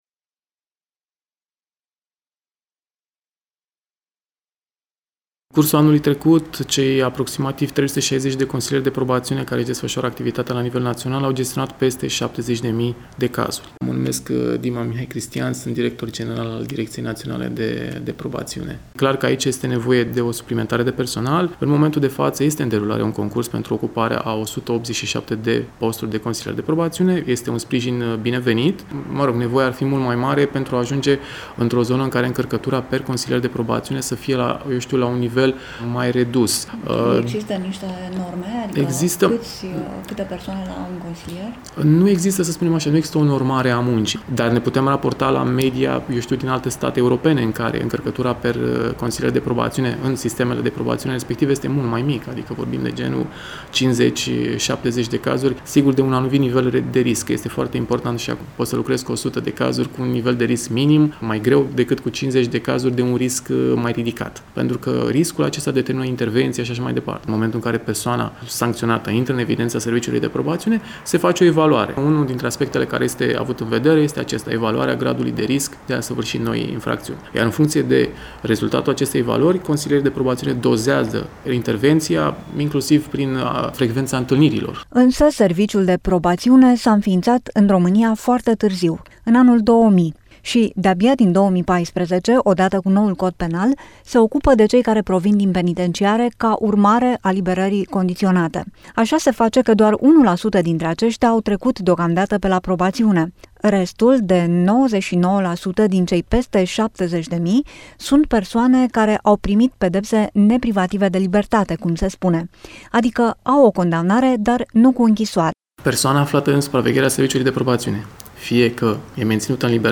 LUMEA-EUROPA-FM-Reportaj-Detinuti-2.mp3